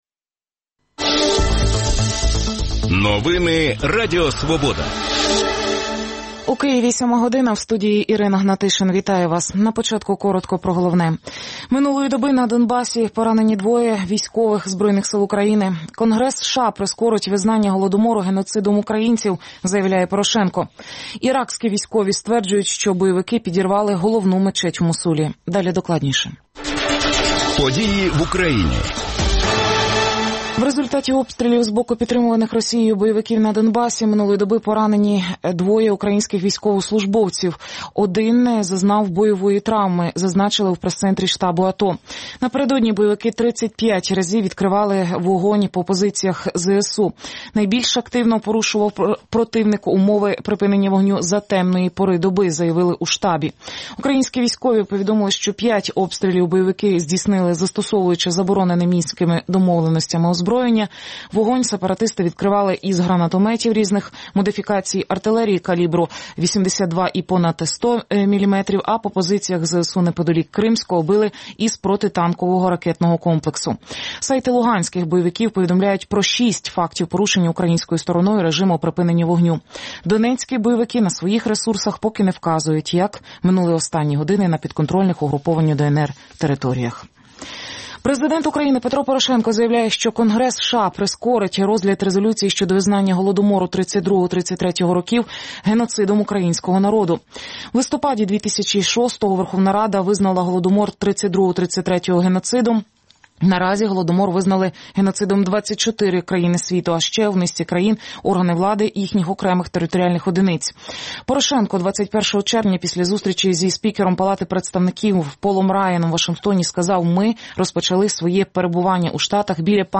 гости студии